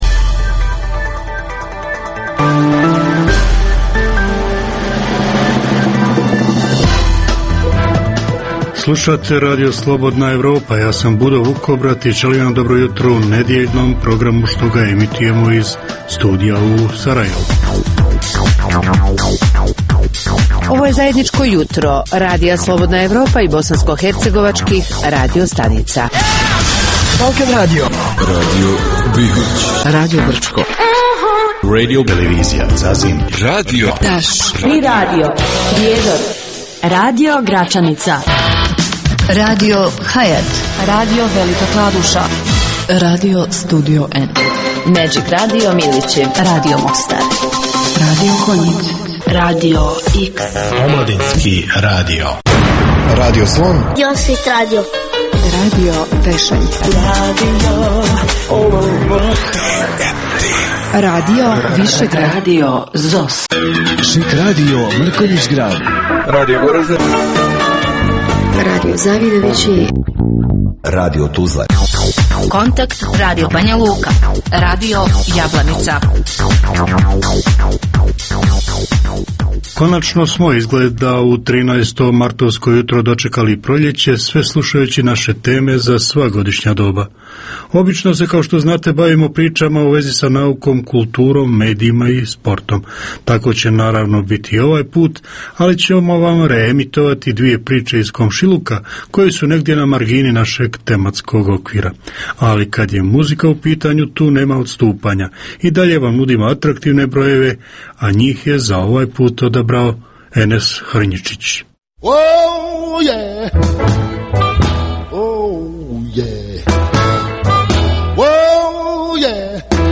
Jutarnji program namijenjen slušaocima u Bosni i Hercegovini. Uz vijesti i muziku, poslušajte pregled novosti iz nauke i tehnike, te čujte šta su nam pripremili novinari RSE iz Zagreba i Beograda.